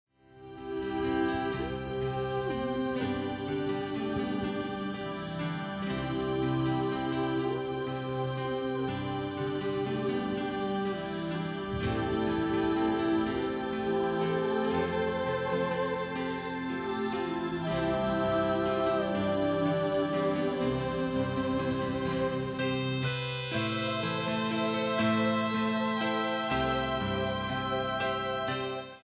Her style ranges between New Age and Electronic.
This CD is a live recording.